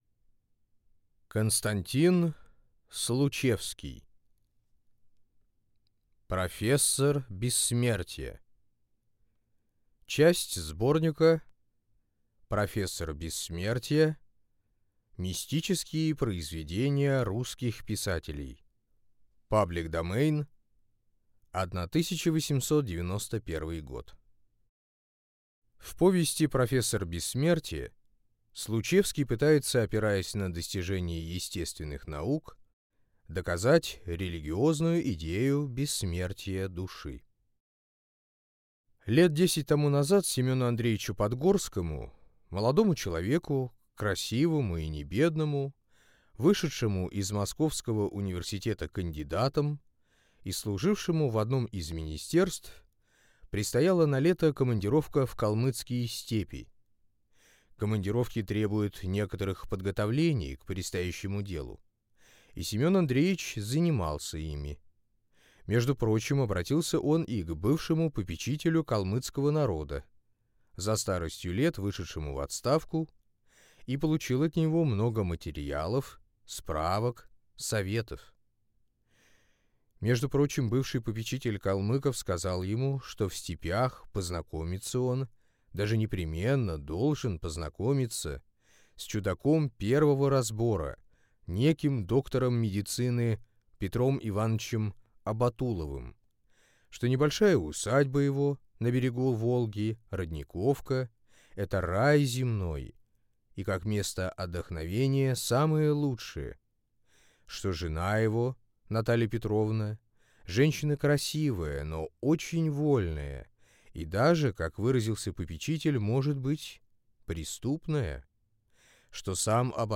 Аудиокнига Профессор бессмертия | Библиотека аудиокниг